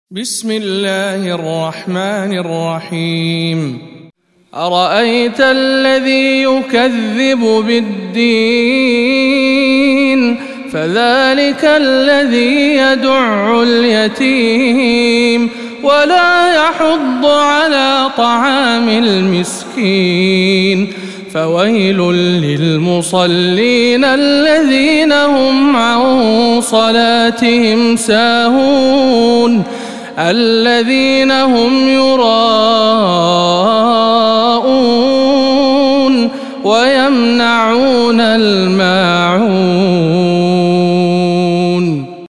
سورة الماعون - رواية ابن ذكوان عن ابن عامر